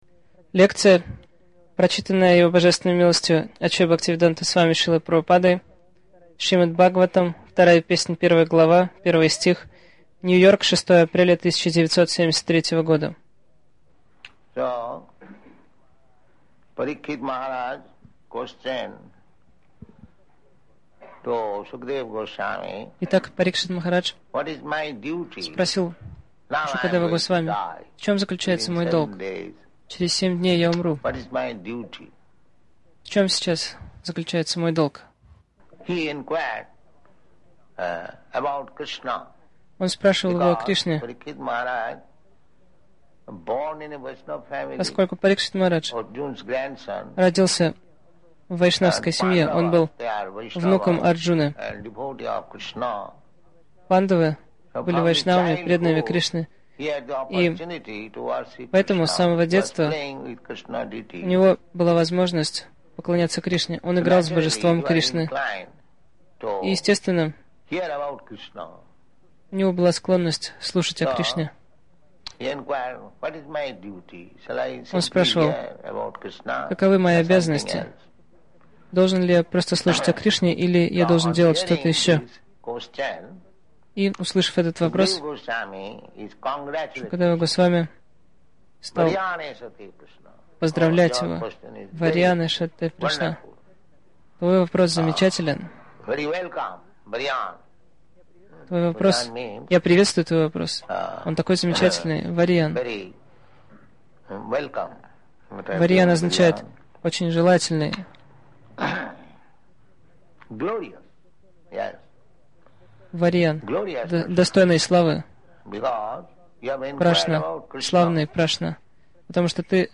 Милость Прабхупады Аудиолекции и книги 06.04.1973 Шримад Бхагаватам | Нью-Йорк ШБ 02.01.01 Загрузка... Скачать лекцию Назад Далее Слушать ещё: ШБ 01.02.08 — Долг человека — служить Богу.